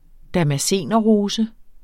Udtale [ damaˈseˀnʌ- ]